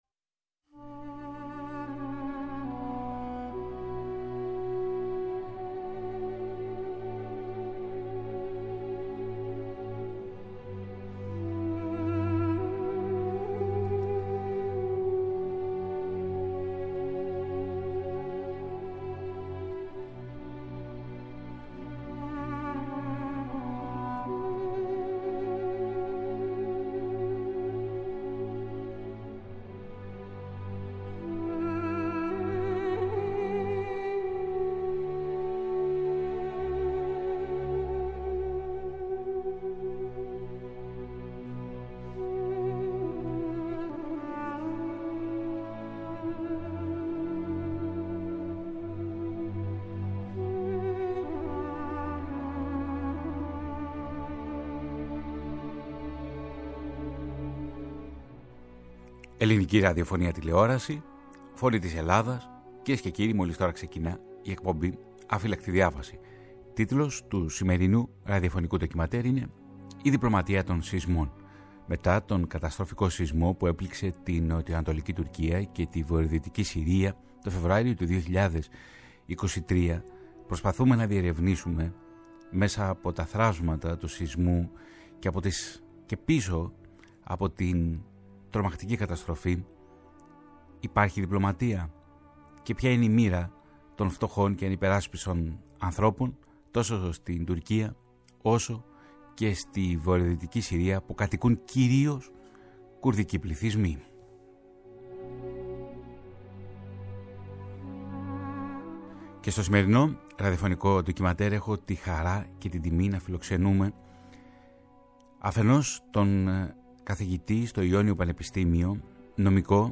Στο ντοκιμαντέρ ακούγονται τέλος μελοποιημένα ποιήματα του Ναζίμ Χικμέτ.